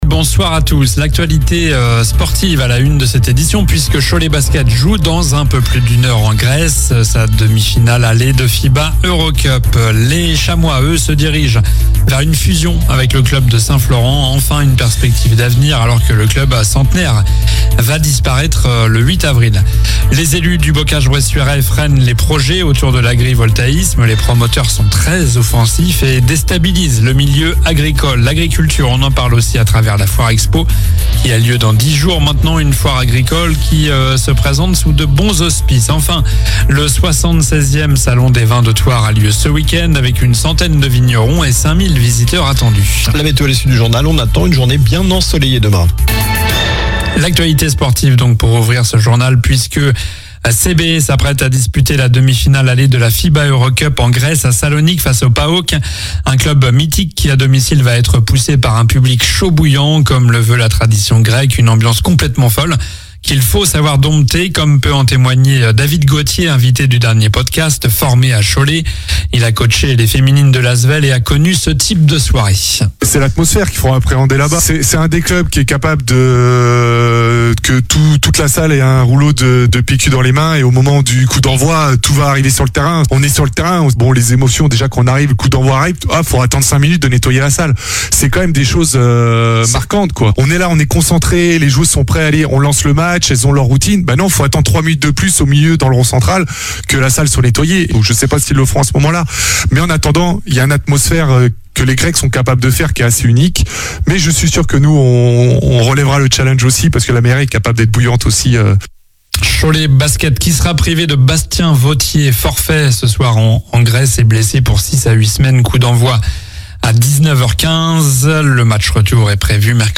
Journal du mercredi 26 mars (soir)